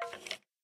skeleton2.ogg